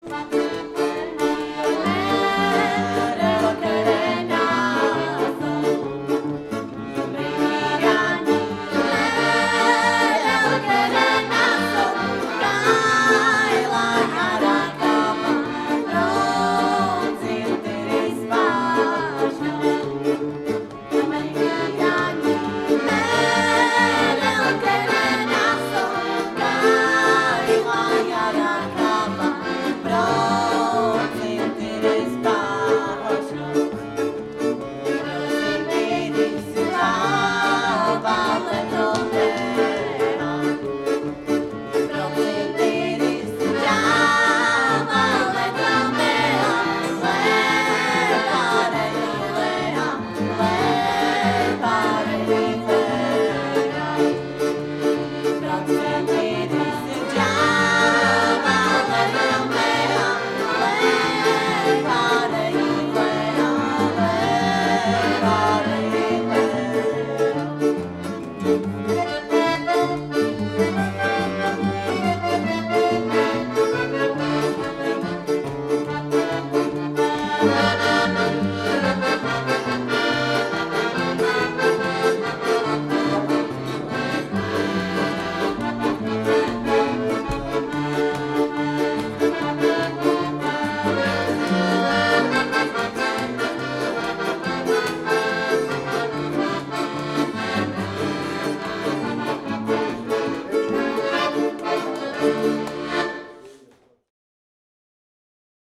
Prajeme vám veselé tancovanie a podupkávanie s rómskymi tanečnými piesňami v neupravovanej autentickej podobe priamo spoza kuchynského stola.